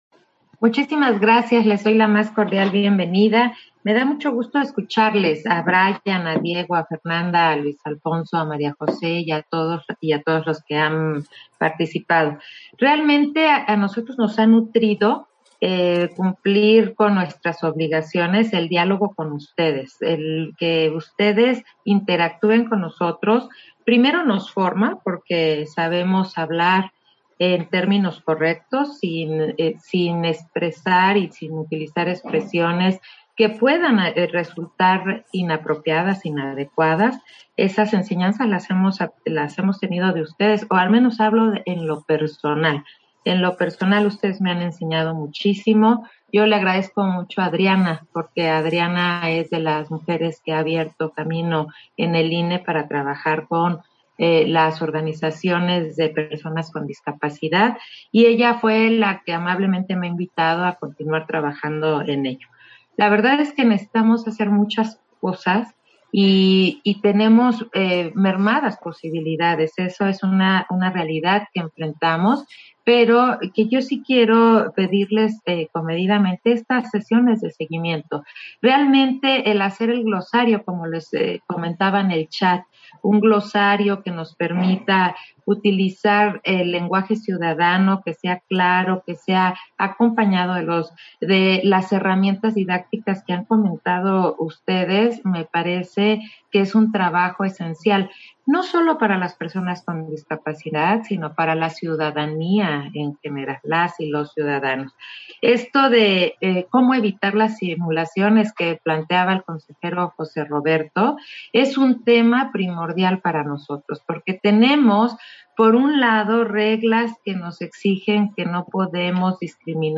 Intervenciones de Consejeras y Consejeros del INE